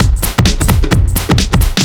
OTG_TripSwingMixC_130b.wav